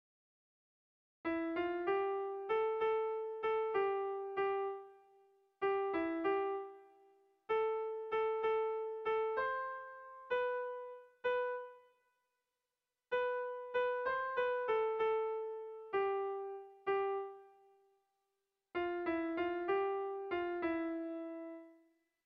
Bertso melodies - View details   To know more about this section
AB